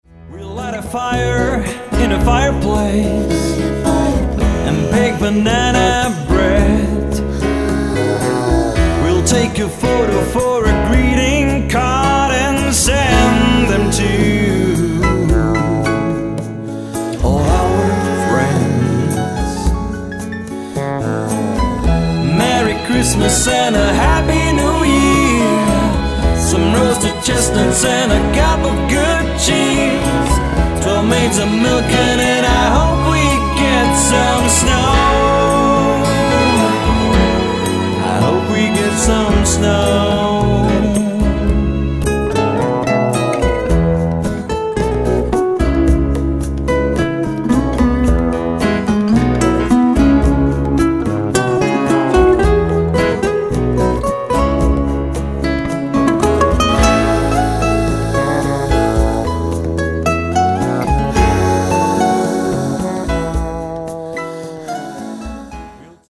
lead, backing vocal
guitars, djembe, percussion
bass
keyboards
violin, whistle
backing vocal